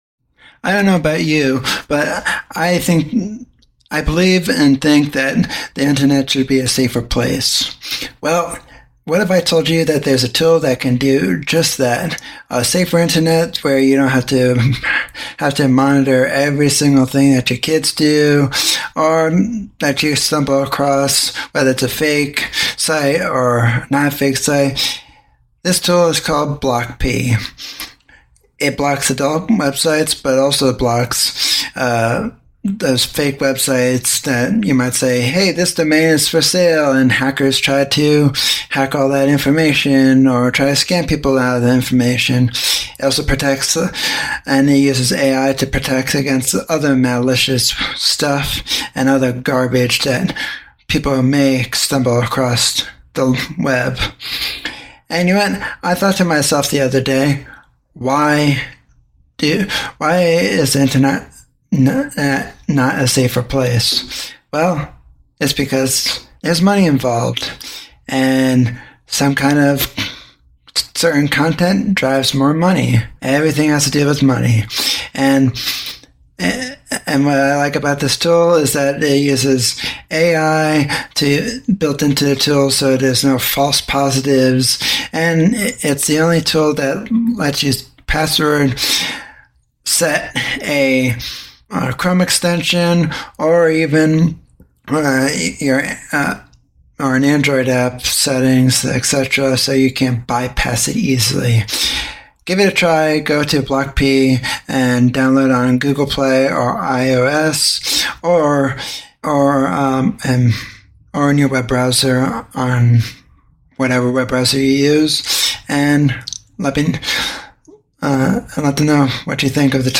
In this heartfelt conversation